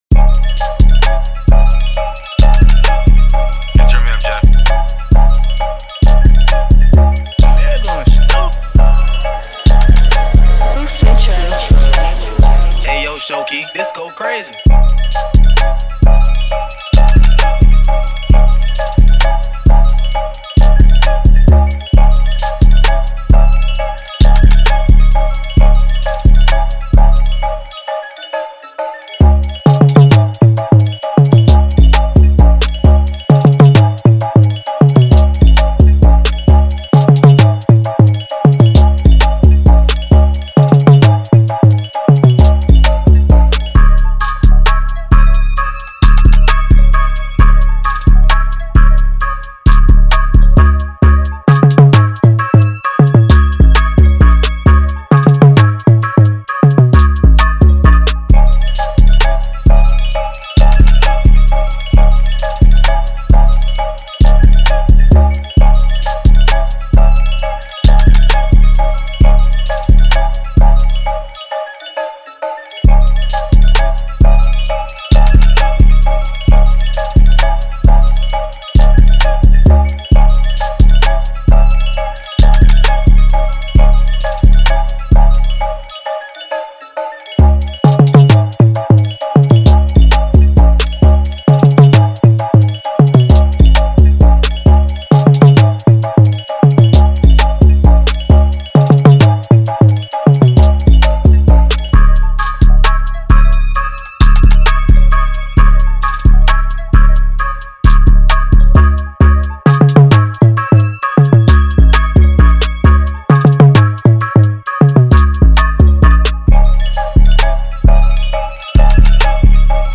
official instrumental
Rap Instrumentals